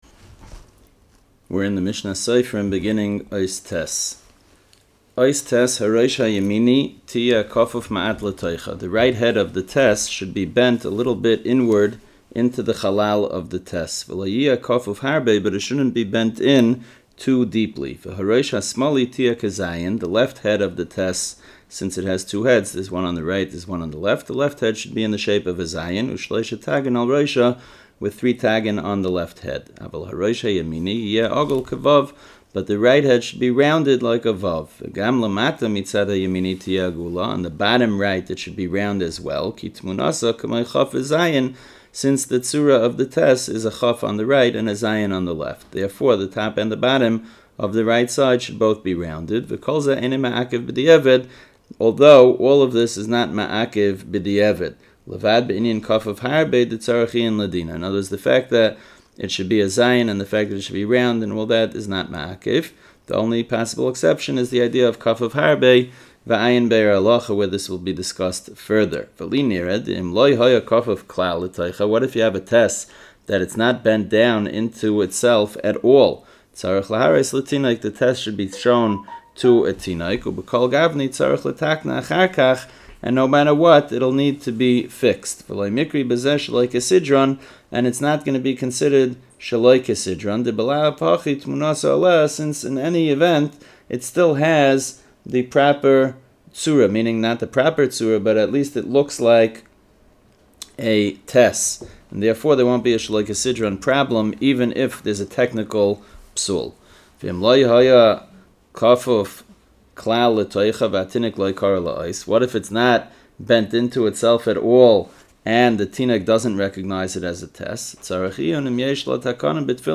A free audio-based course covering the halachos and practical details of Hebrew letter formation in Sta”M. Learn the shapes, laws, and kavanos behind each letter—based on Mishna Berura and Keses HaSofer.